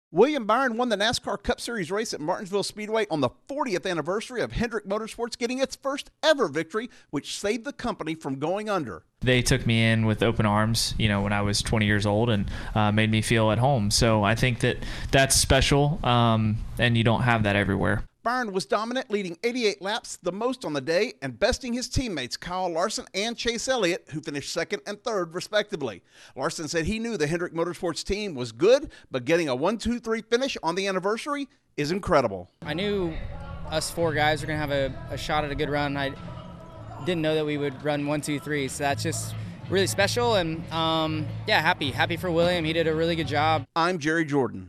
It's a memorable day for one of NASCAR's best ownership groups. Correspondent